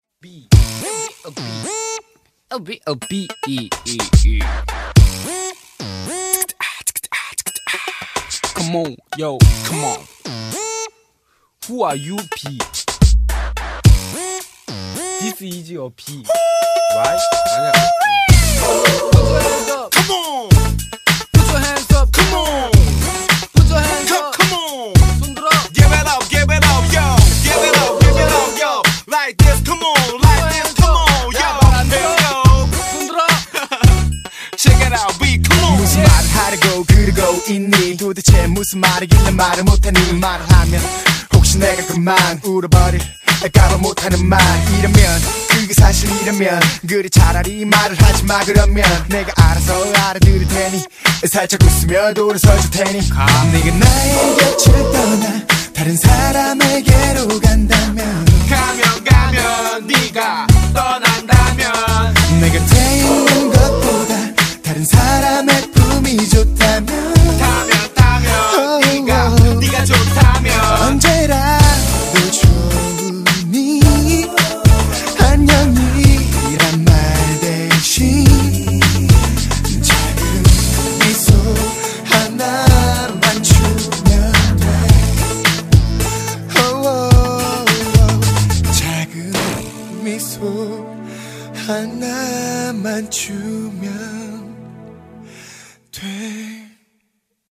BPM108--1
Audio QualityPerfect (High Quality)